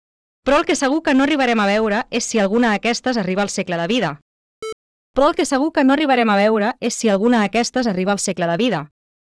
Hello everyone! I would like to improve an audio file from a podcast that was recorded in a radio studio.
There isn’t much background noise that can be removed with noise reduction (when no one is speaking, the audio is pretty quiet). The issue arises when people are speaking, especially with two of the microphones.